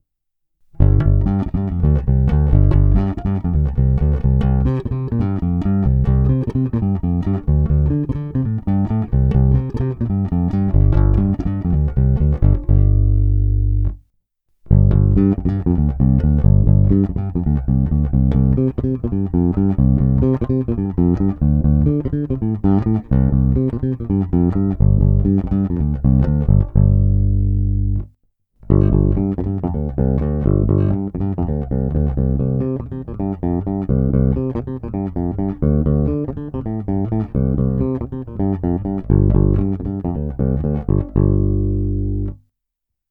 Je hutný, vrčivý, zvonivý, s bohatými středy, s příjemnými výškami a masívními basy.
Není-li uvedeno jinak, následující ukázky jsou pořízeny rovnou do vstupu zvukové karty a kromě normalizace ponechány bez jakéhokoli postprocesingu.